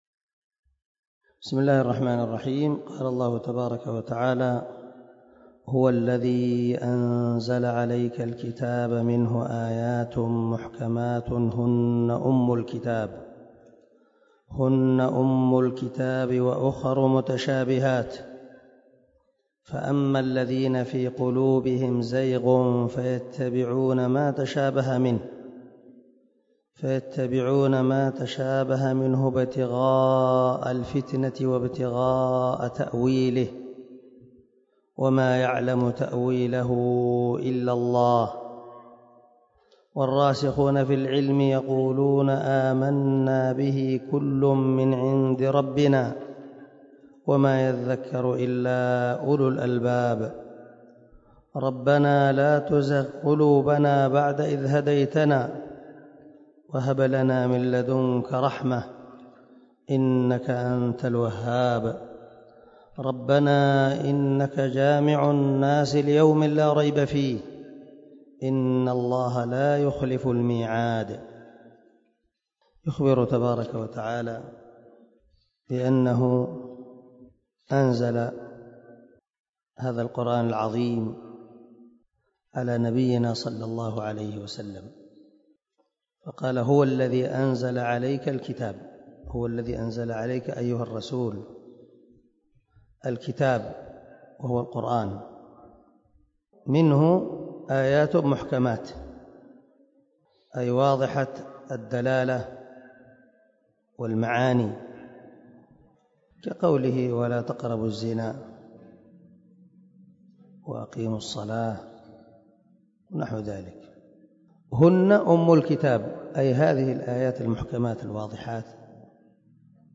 157الدرس 2 تفسير آية ( 7 – 9 ) من سورة آل عمران من تفسير القران الكريم مع قراءة لتفسير السعدي